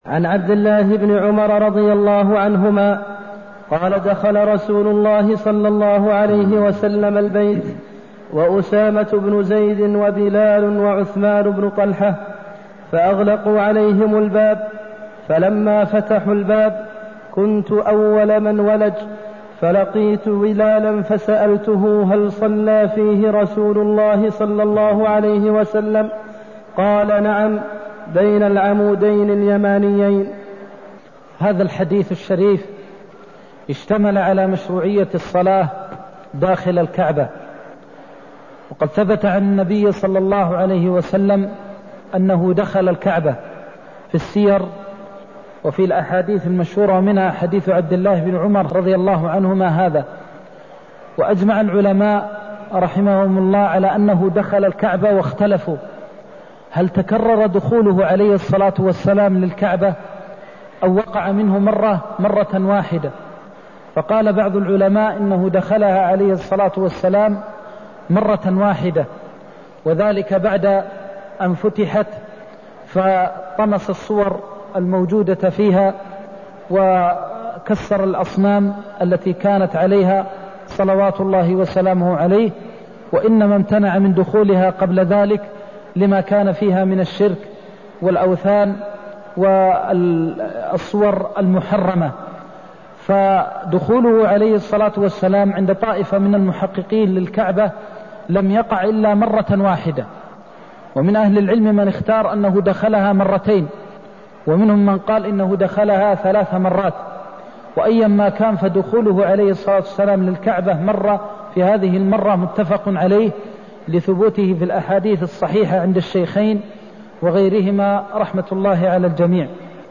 المكان: المسجد النبوي الشيخ: فضيلة الشيخ د. محمد بن محمد المختار فضيلة الشيخ د. محمد بن محمد المختار دخل رسول الله البيت وأسامة بن زيد وبلال وعثمان بن طلح (214)